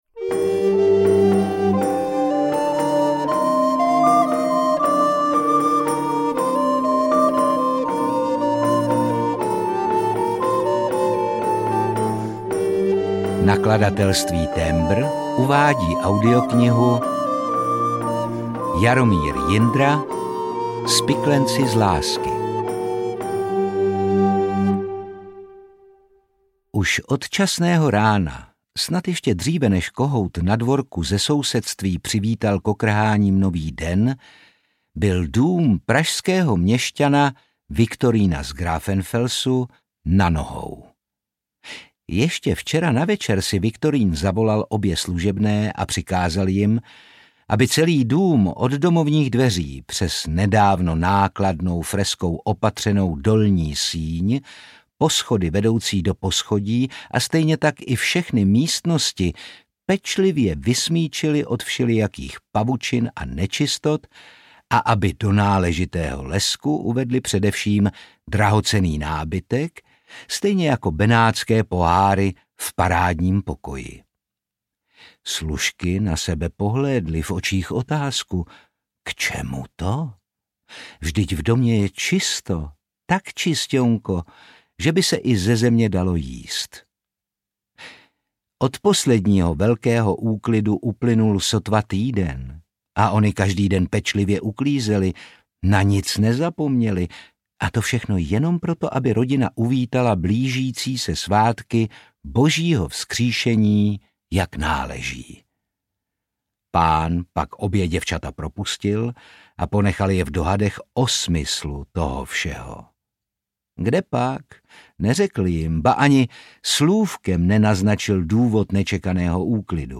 Spiklenci z lásky audiokniha
Ukázka z knihy
• InterpretOtakar Brousek ml.